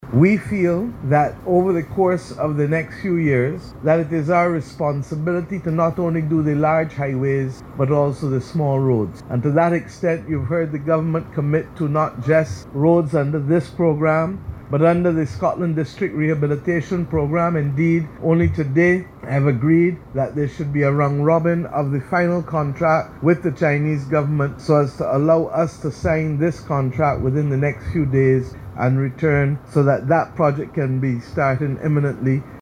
Speaking at the ground-breaking ceremony at Carmichael Road, St. George for the Road Works Global Project, Prime Minister Mia Amor Mottley, noted that more than sixty roads have been repaired since 2018 and said the intensive program will continue.